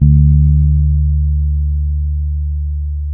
-MM DUB  D.3.wav